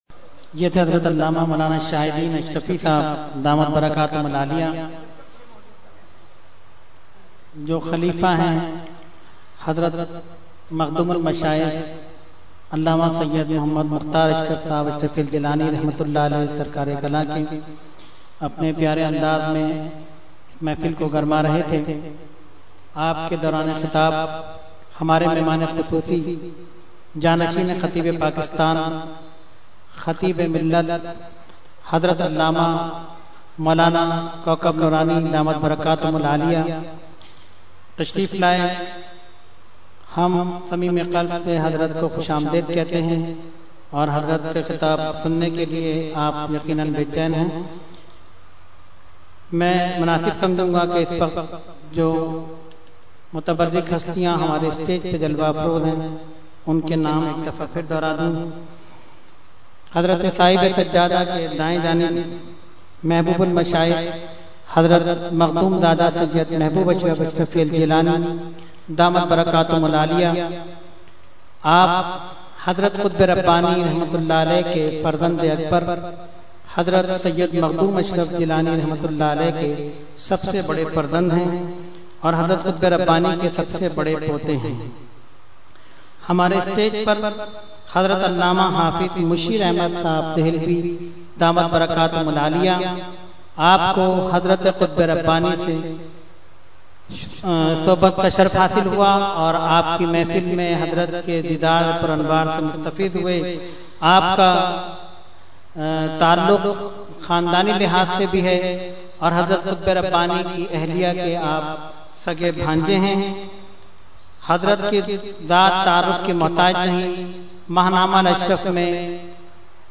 Short Speech – Urs Qutbe Rabbani 2012 – Dargah Alia Ashrafia Karachi Pakistan
Category : Speech | Language : UrduEvent : Urs Qutbe Rabbani 2012